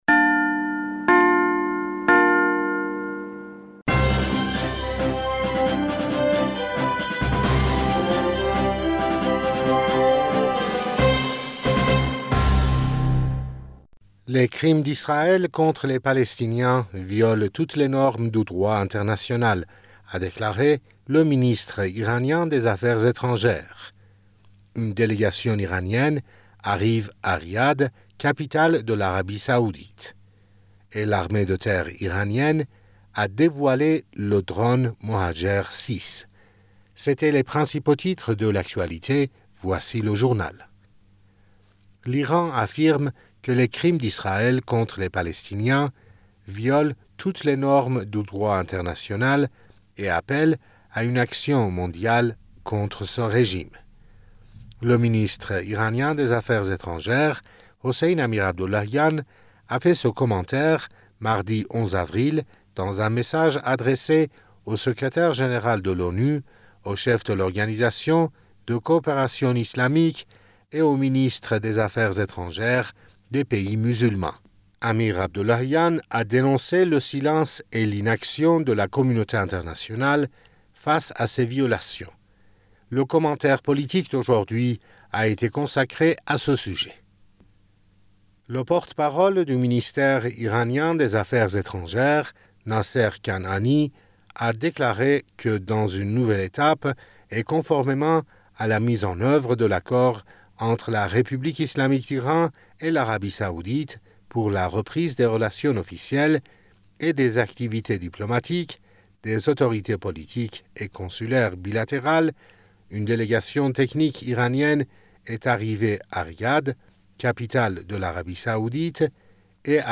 Bulletin d'information du 12 Avril 2023